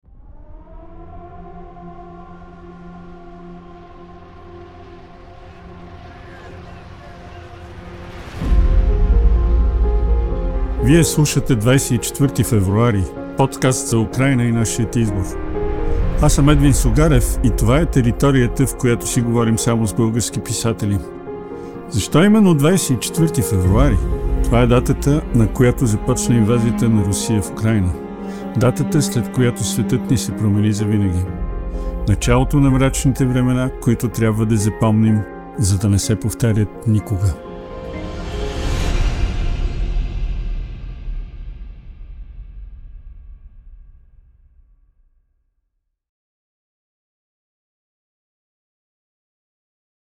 записите са направени в: Sintez Podcast Studio